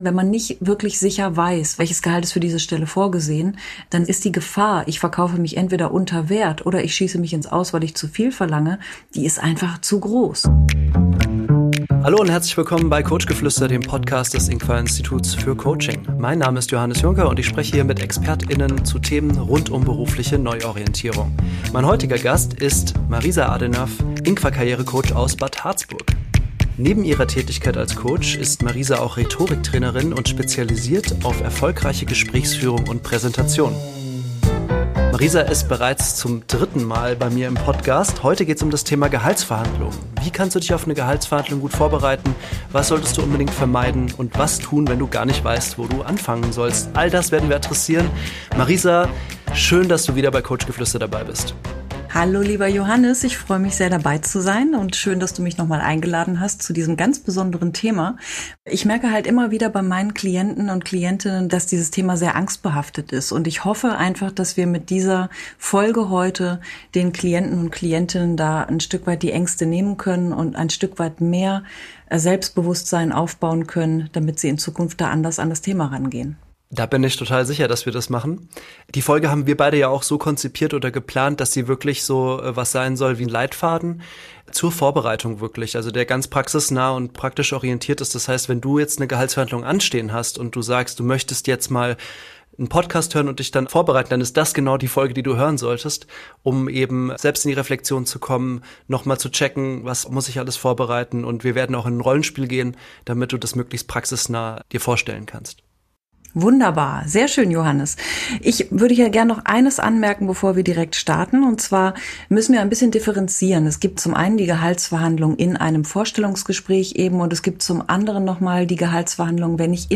Fit für die Gehaltsverhandlung im Vorstellungsgespräch (mit Rollenspiel) ~ INQUA COACHGEFLÜSTER Podcast